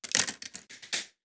06708 slot machine coin inserting ---
coin credit flipper game insert machine sfx slot sound effect free sound royalty free Gaming